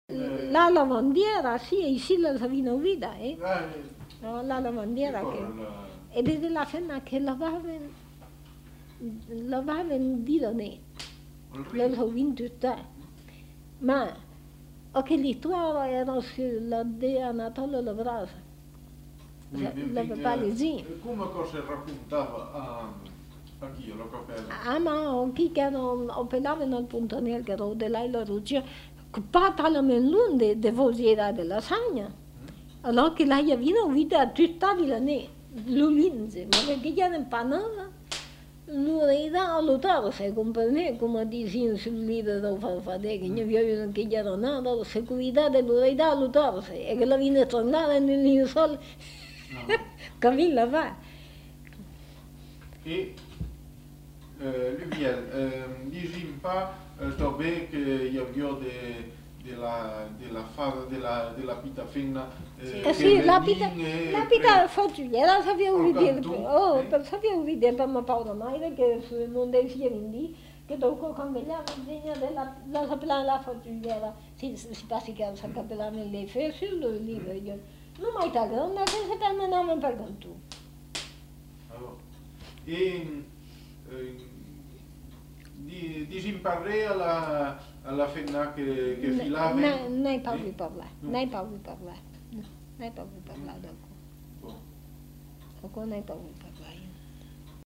Aire culturelle : Périgord
Lieu : La Chapelle-Aubareil
Genre : conte-légende-récit
Effectif : 1
Type de voix : voix de femme
Production du son : parlé